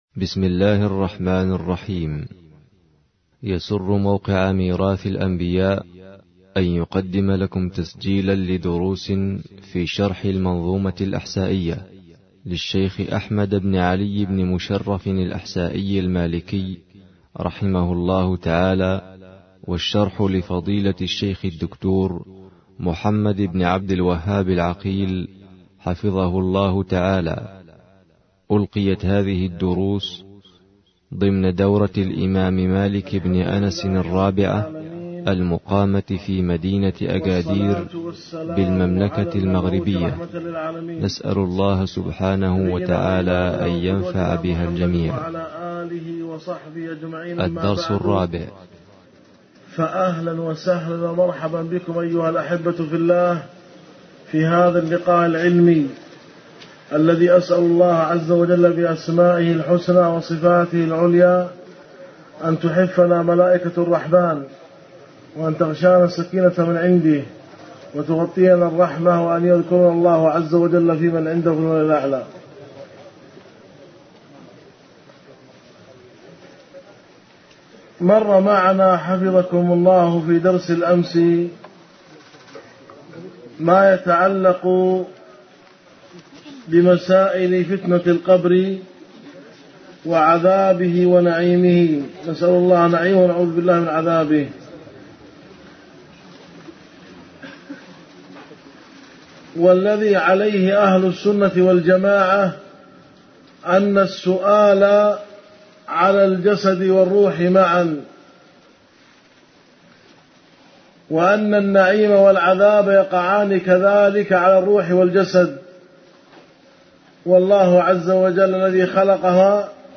الدرس 4